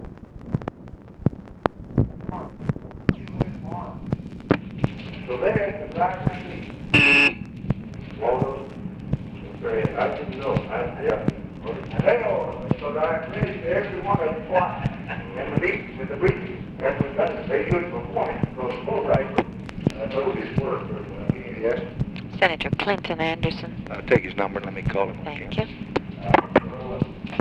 Conversation with OFFICE SECRETARY and OFFICE CONVERSATION
Secret White House Tapes | Lyndon B. Johnson Presidency